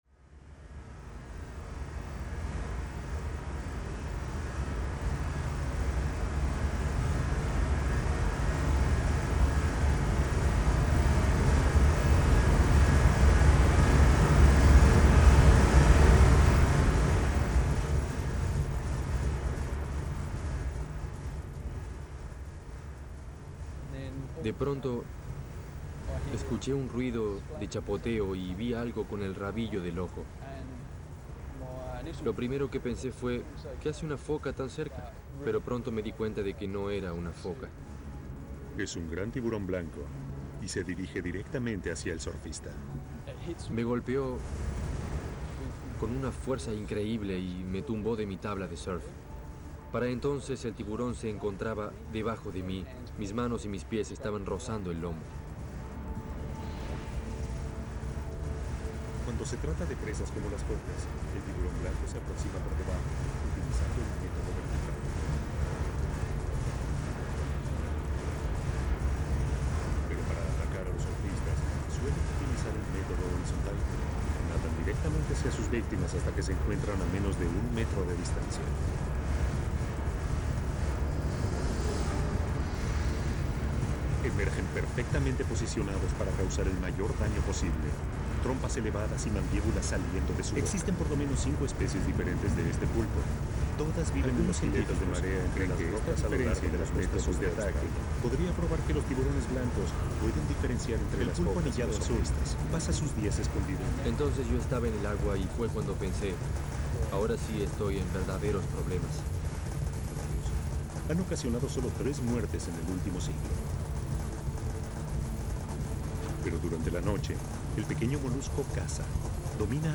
TV sounds project
WORK IN PROGRESS first tryouts The aim is to take the TV sounds out. Alter with electroacoustic sounds.
Intervention of Tv sounds about the most dangerous animals in the world